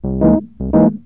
05 Rhodes 15.wav